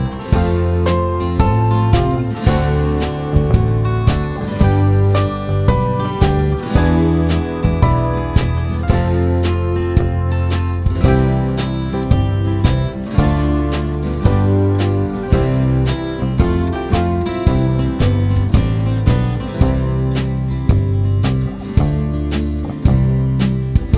2024 Remake Pop (1970s) 3:36 Buy £1.50